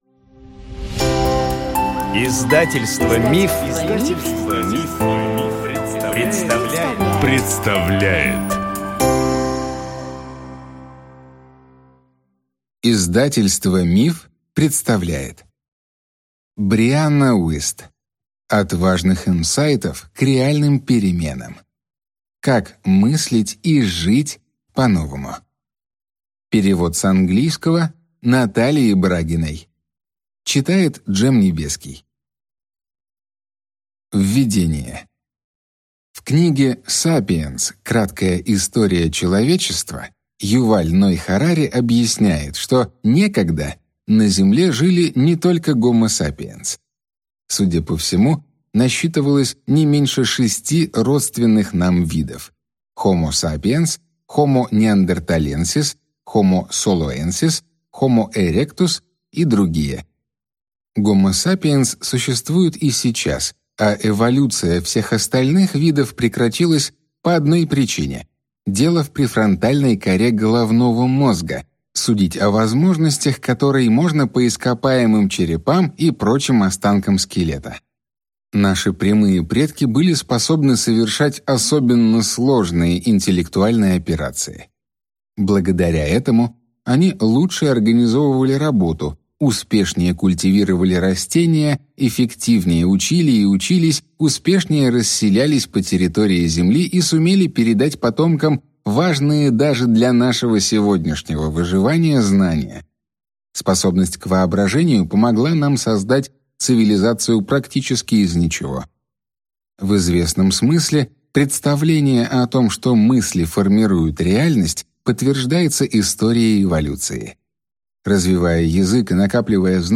Аудиокнига От важных инсайтов к реальным переменам | Библиотека аудиокниг